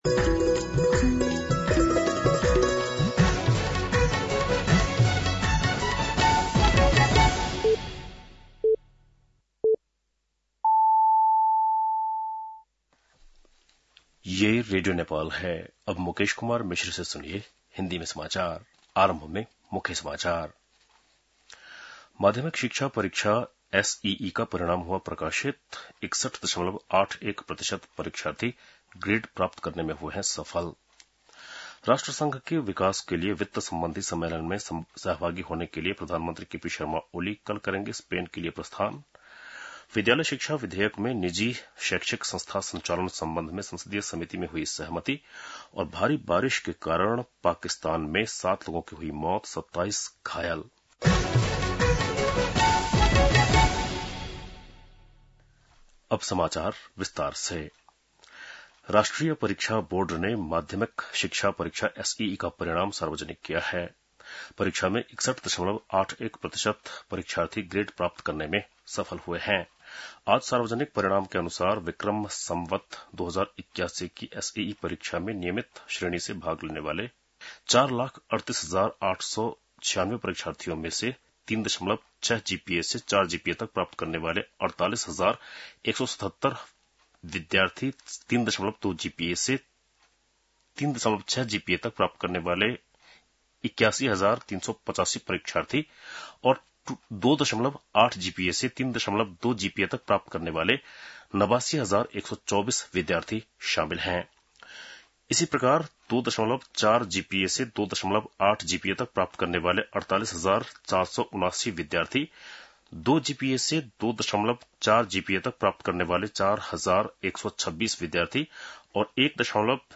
बेलुकी १० बजेको हिन्दी समाचार : १३ असार , २०८२